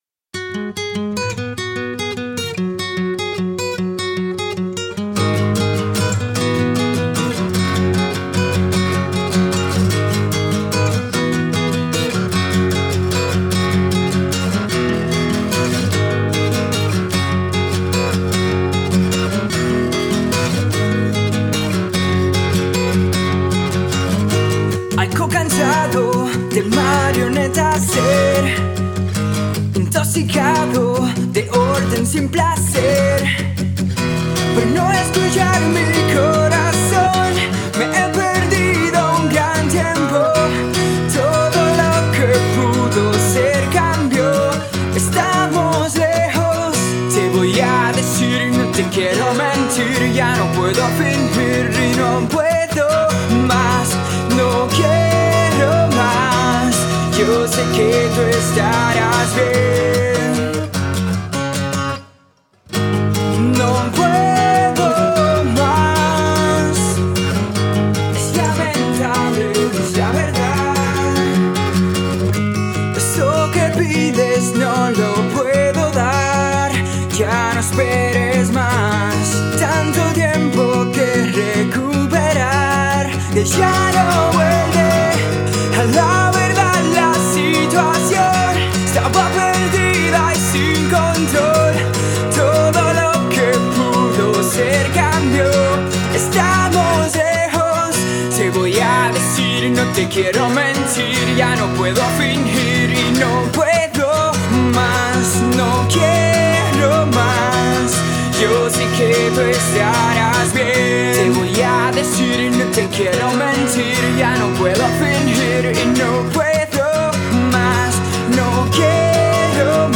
• Genre: Poprock